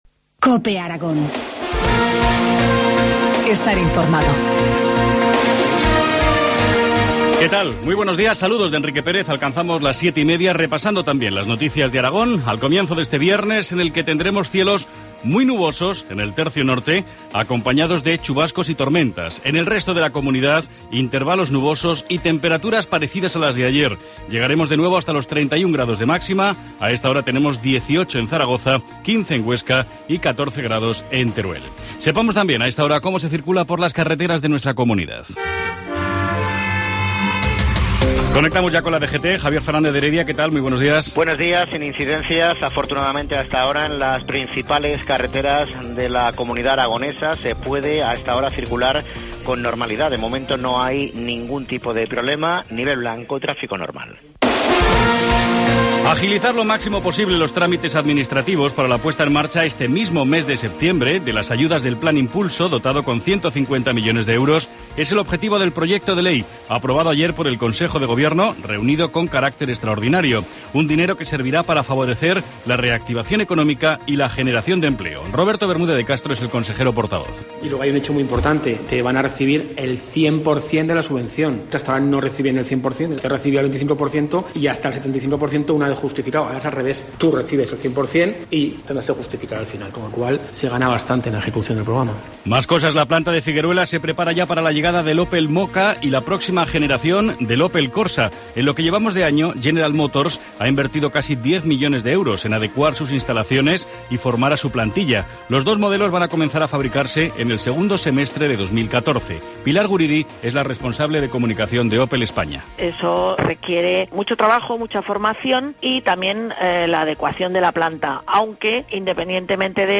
Informativo matinal, viernes 6 de septiembre, 7.25 horas